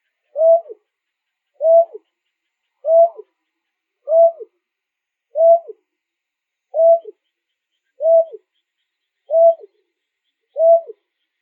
「日本の鳥百科」タマシギの紹介です（鳴き声あり）。